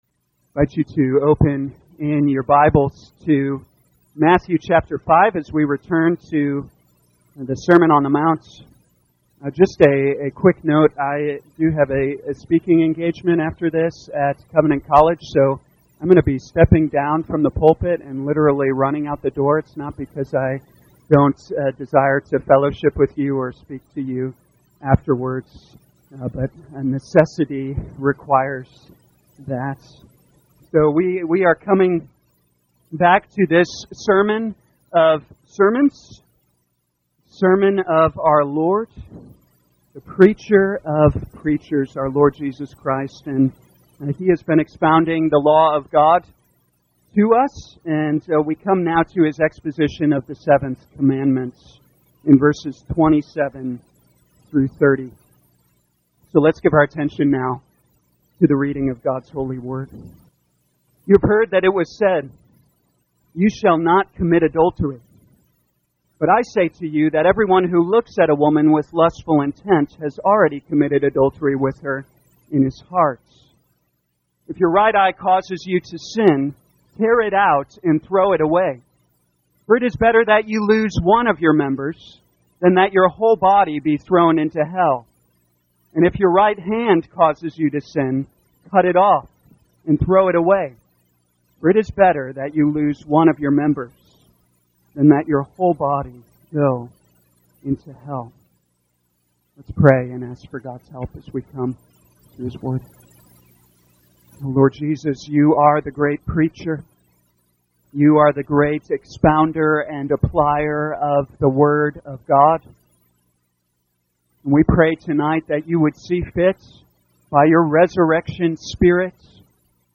2023 Matthew Evening Service Download